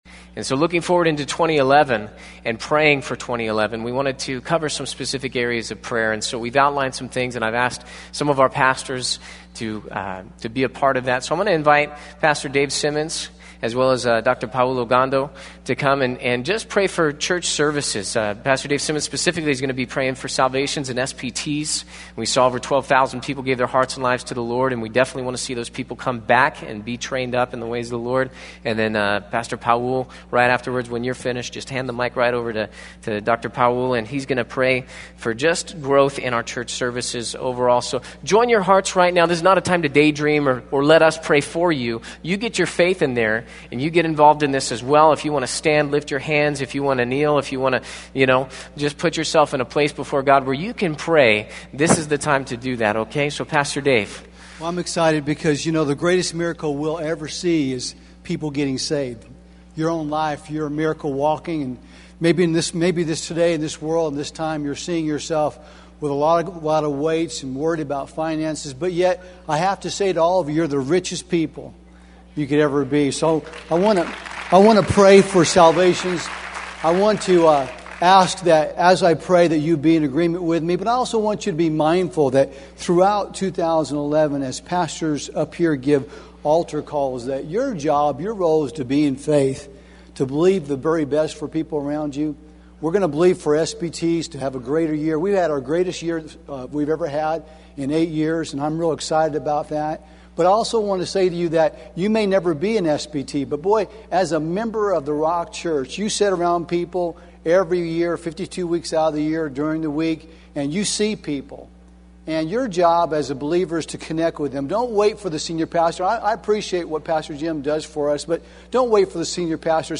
The Rock Church | New Year's Eve Prayer Service